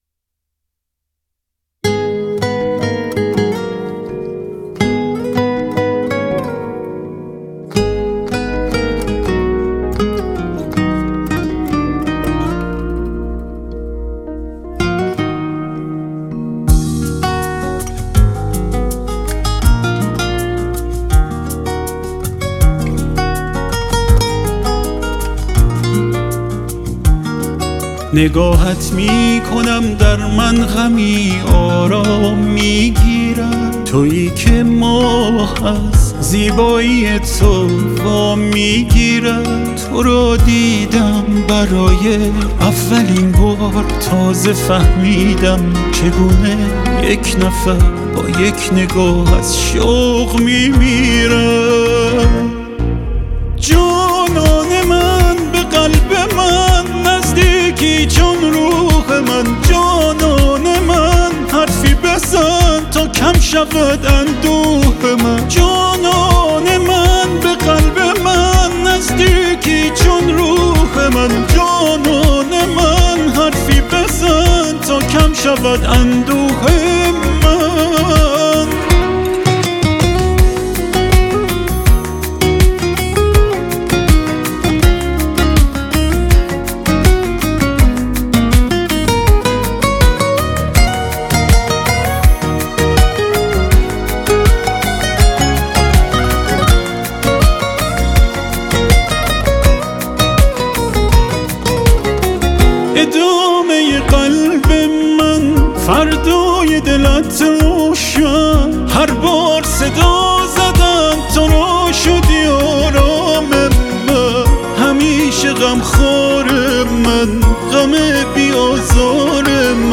صدای بلورین
مثل همیشه عالی صدایی از جنس نور
عالی وباحساس